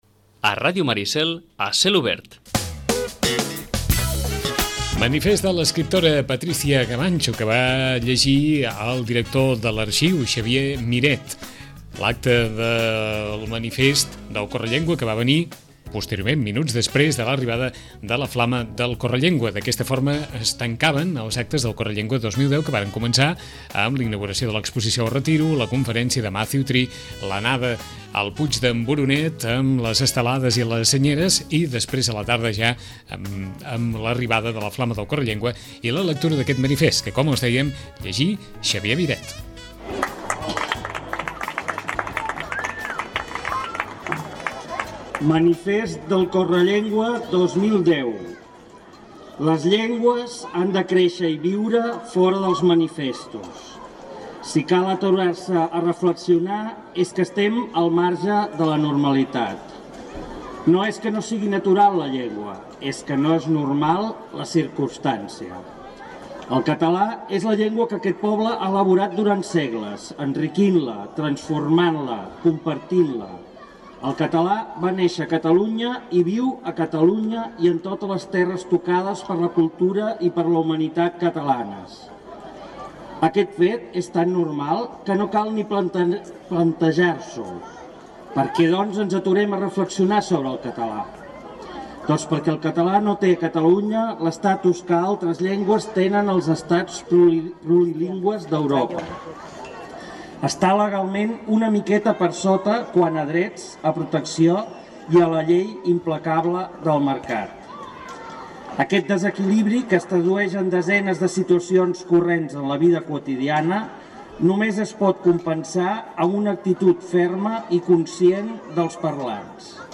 llegí el manifest del Correllengua 2010